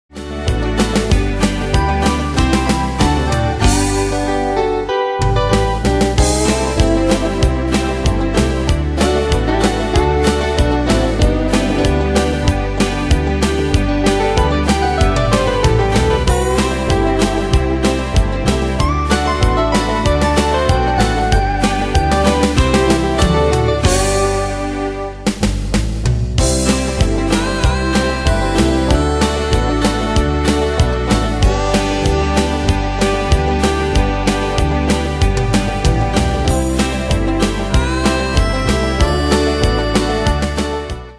Demos Midi Audio
Style: Country Année/Year: 1997 Tempo: 95 Durée/Time: 3.21
Danse/Dance: Swing Cat Id.
Pro Backing Tracks